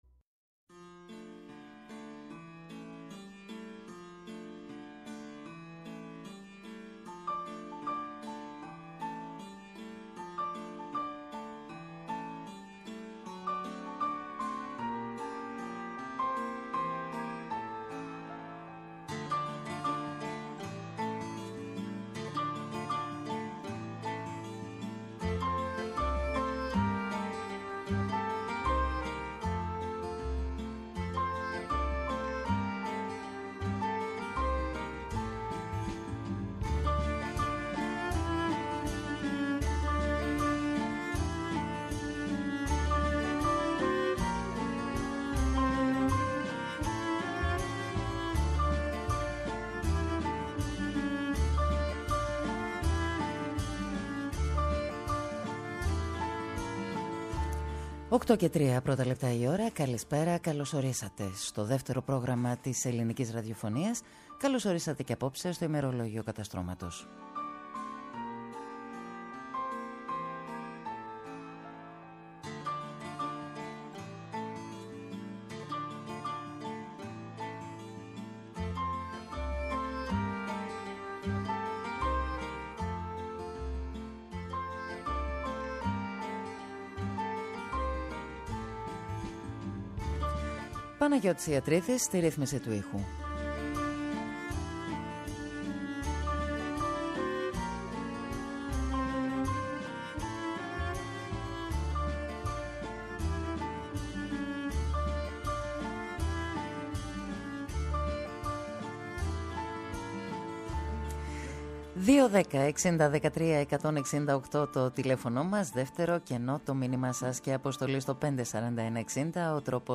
ΔΕΥΤΕΡΟ ΠΡΟΓΡΑΜΜΑ Μουσική Συνεντεύξεις